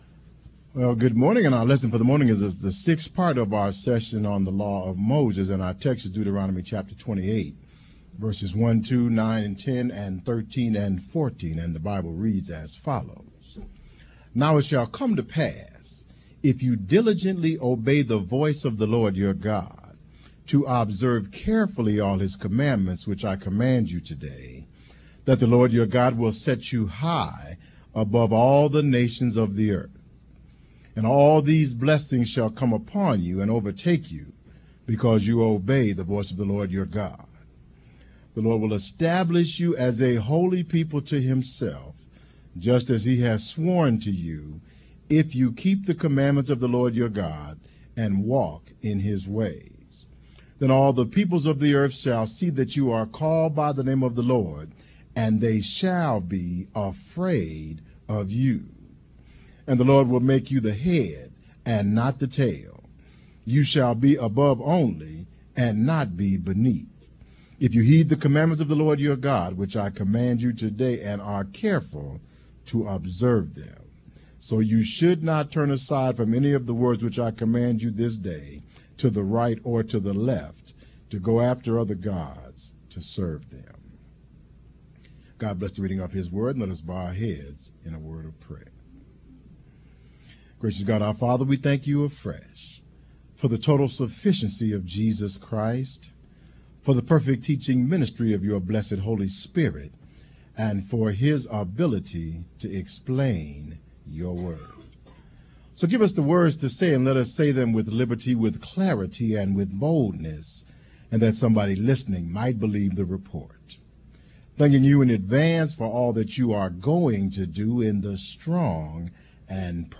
Audio Download: Click to download Audio (mp3) Additional Downloads: Click to download Sermon Text (pdf) Content Feeds Use the links below to subscribe to our regularly produced audio and video content.